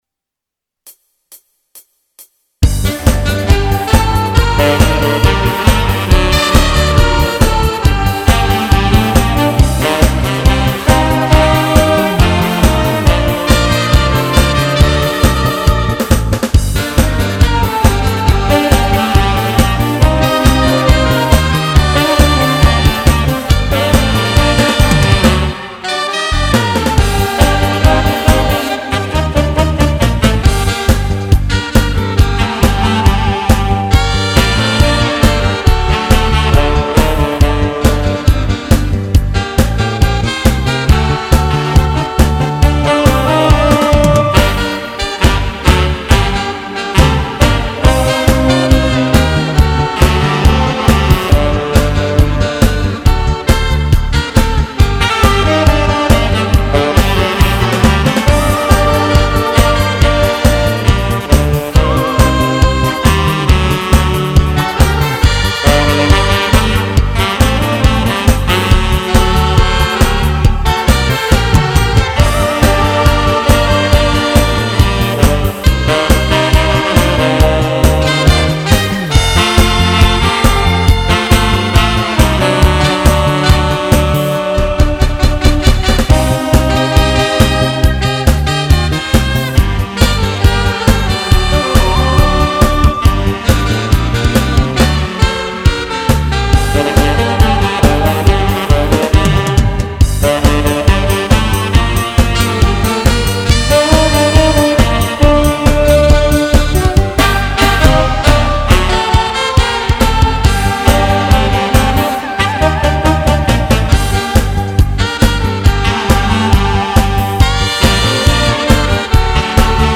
색소폰 연주회, 가을을 노래했다.
알토 색소폰 1,2부가 있고, 테너 파트가 있어 총 15명으로 구성되어 있다.
*합주곡 중 한 곡을 올려 놓았습니다.